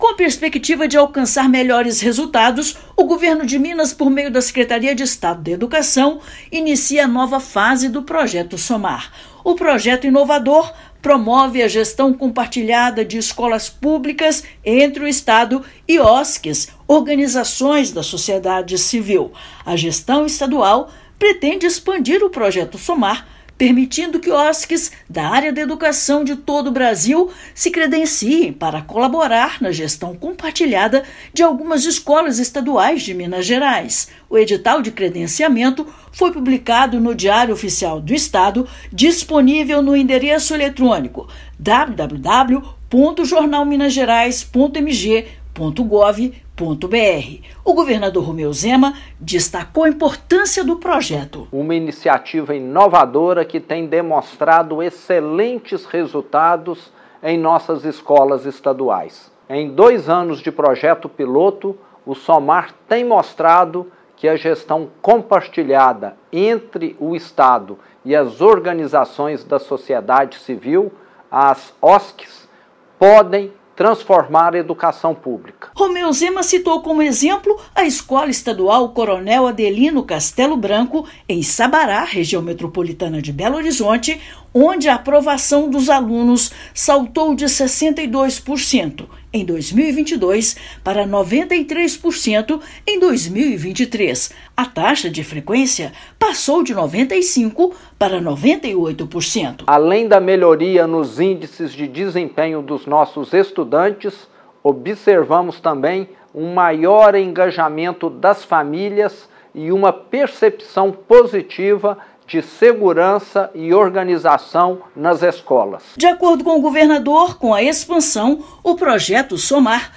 [RÁDIO] Governo de Minas expande Projeto Somar para gestão compartilhada em escolas estaduais
Escolas participantes do projeto-piloto apresentaram melhorias significativas no desempenho dos estudantes e na gestão. Ouça matéria de rádio.